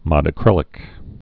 (mŏdə-krĭlĭk)